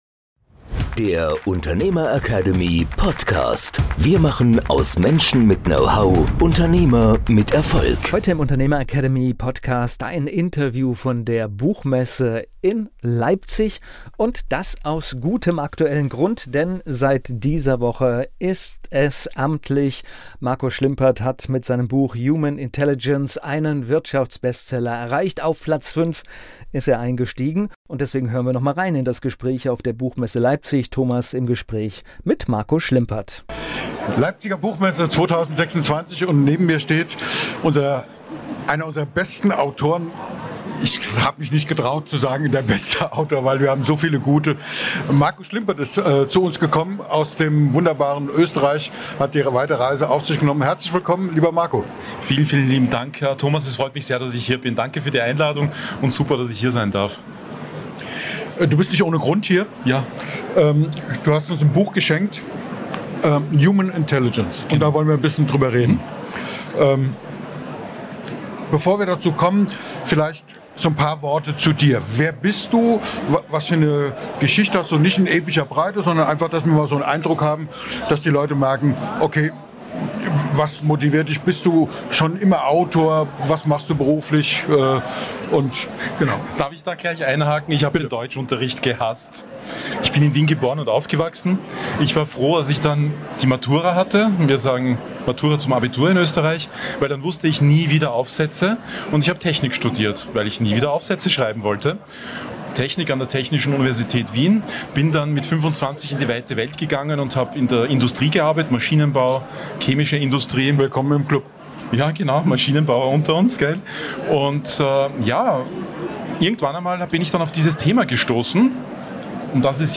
In dieser Episode des Unternehmer Academy Podcasts nehmen wir Sie mit auf die Leipziger Buchmesse 2026.